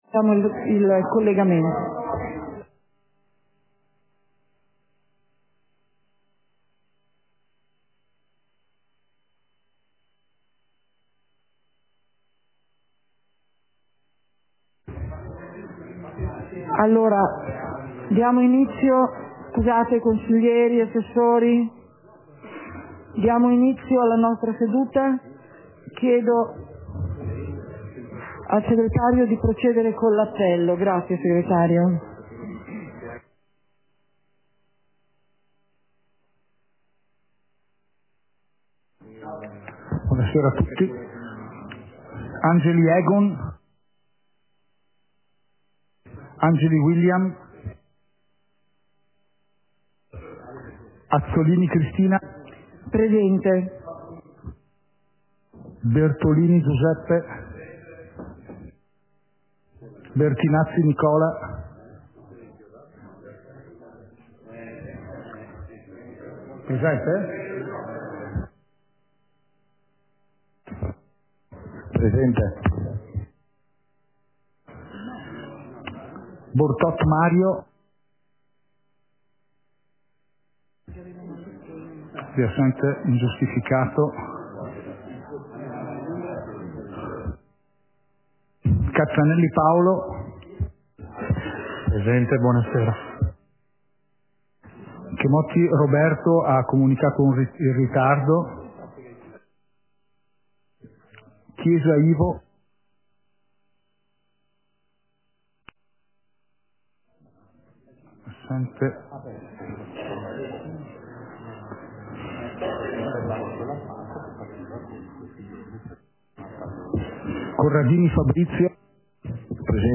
Seduta del consiglio comunale - 19.04.2023